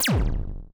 shot.wav